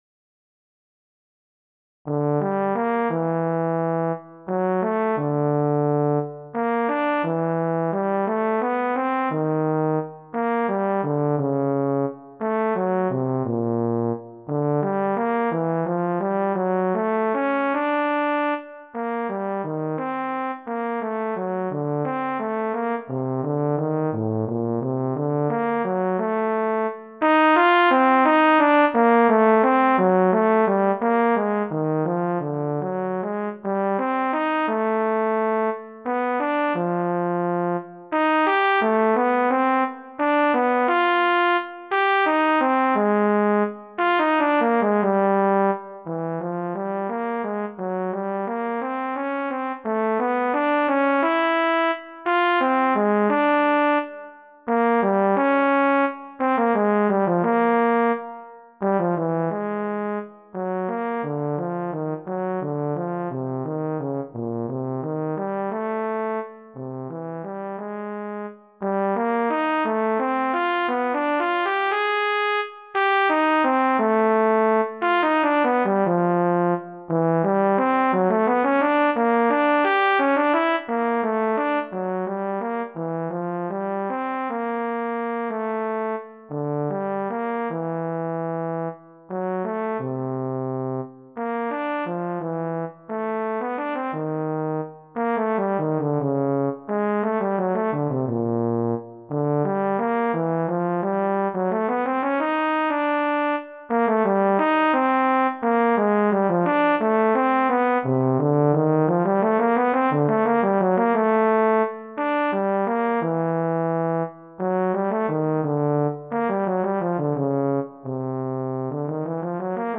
Pour saxhorn alto ou autre cuivre solo, sans accompagnement.